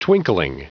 Prononciation du mot twinkling en anglais (fichier audio)
Prononciation du mot : twinkling